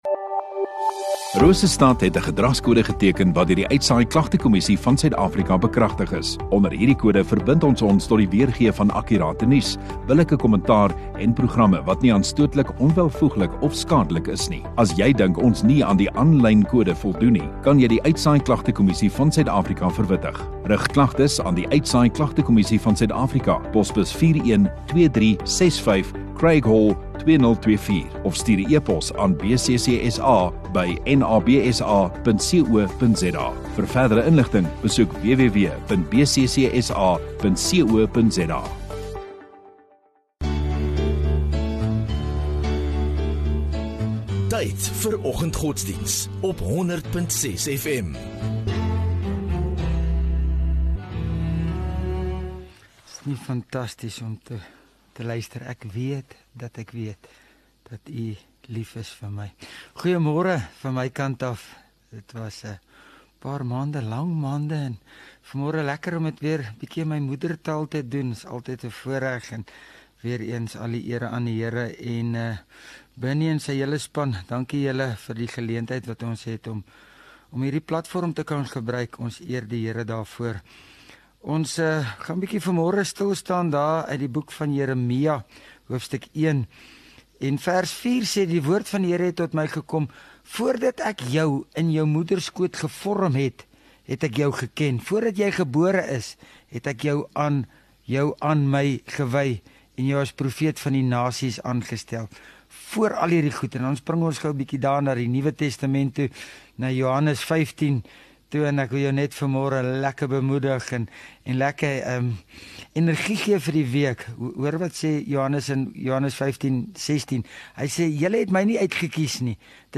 25 Aug Maandag Oggenddiens